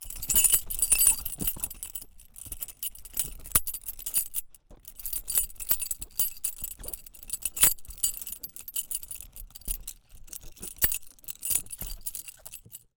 Key Door Moving Sound
household